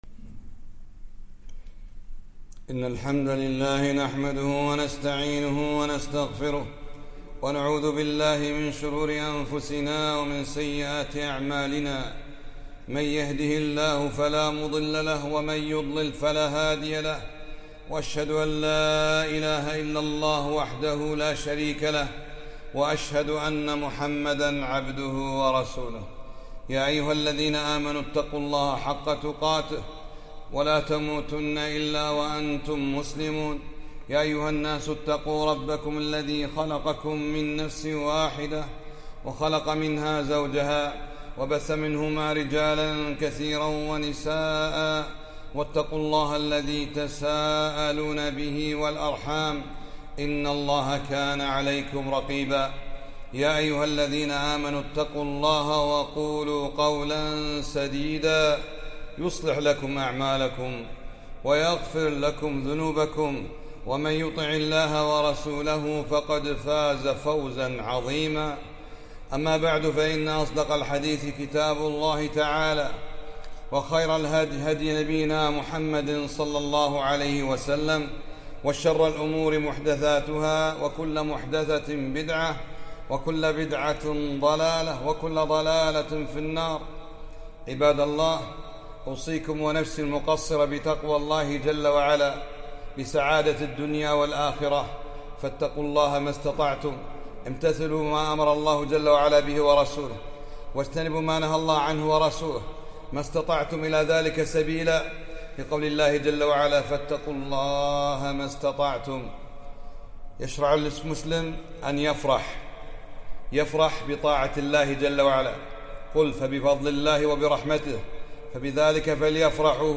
خطبة - محبة النبي ﷺ بالاتباع لا بإقامة المولد